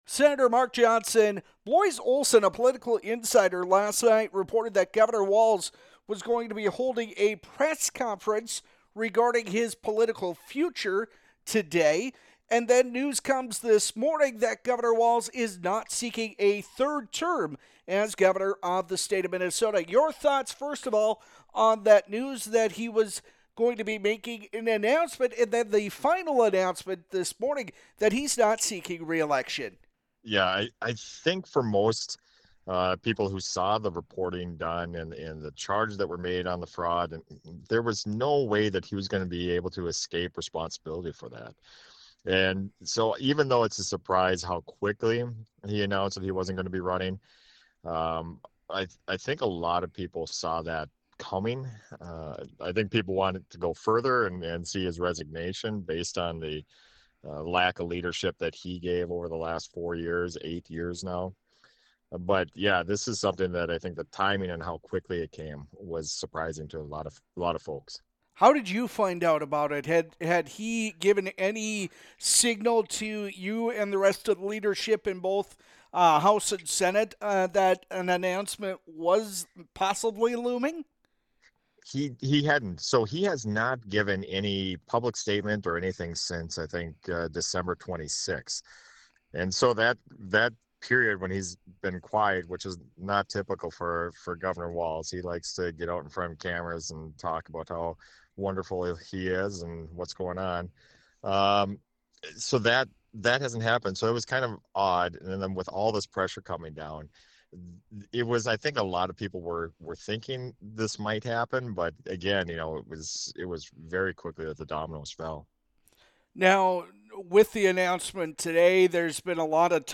In a phone interview Monday morning with Flag Family News, Senate Minority Leader Mark Johnson, a Republican from East Grand Forks, said there was ‘no way’ Walz would be able to escape responsibility for the alleged fraud.